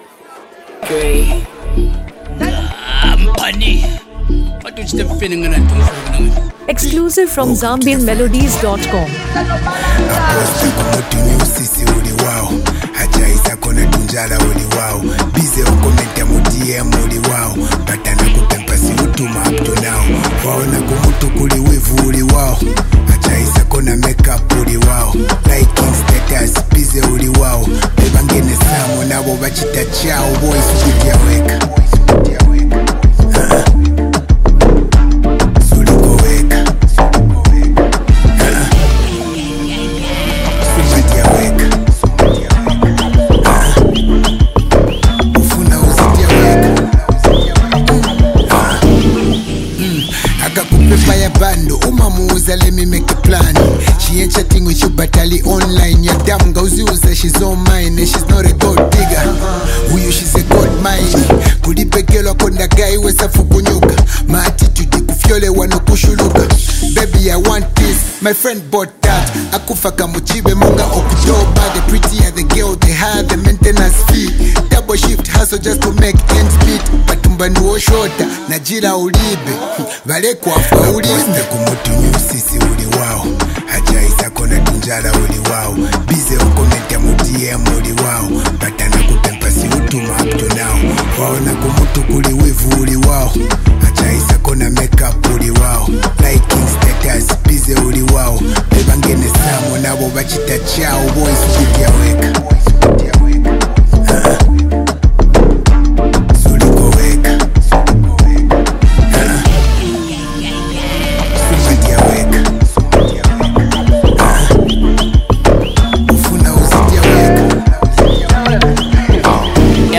sharp verses filled with witty storytelling
emotional, melodic vocals